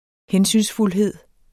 Udtale [ ˈhεnsynsfulˌheðˀ ]